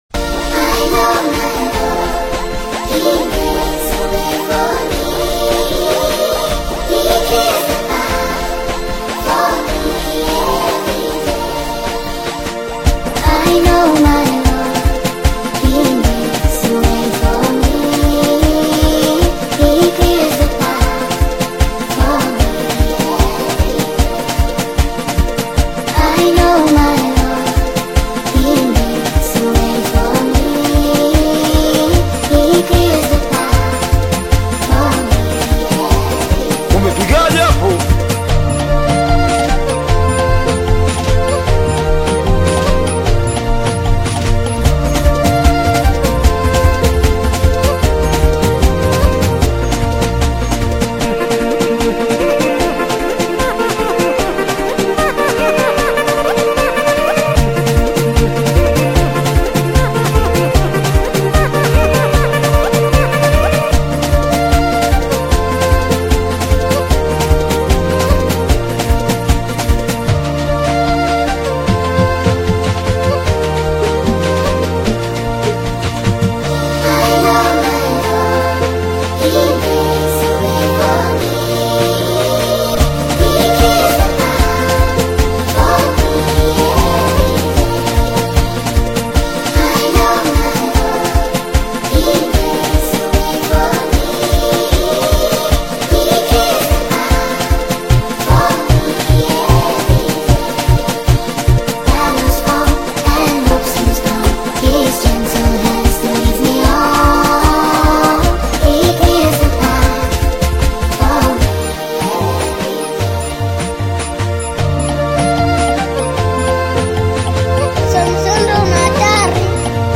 dynamic delivery and vibrant style